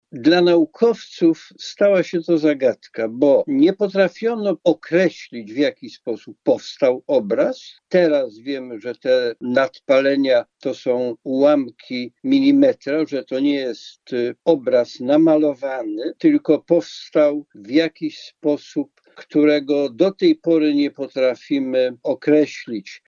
fotograf i publicysta.